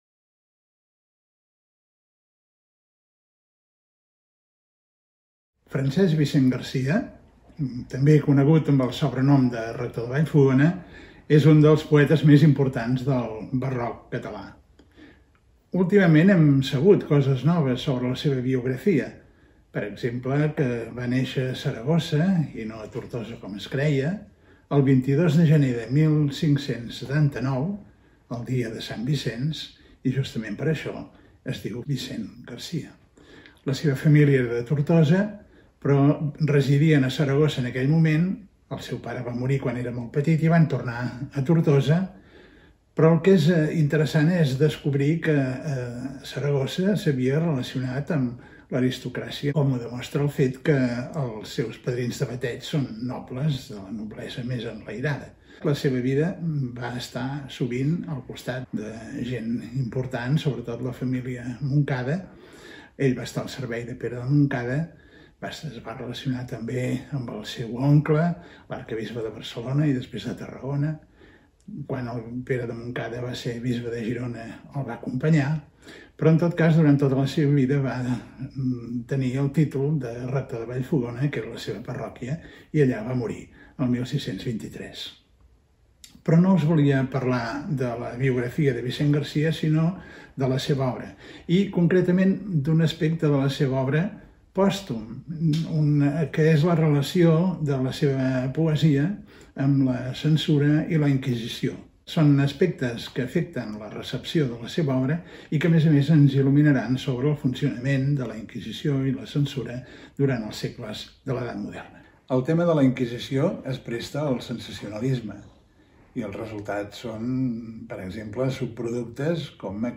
Classe magistral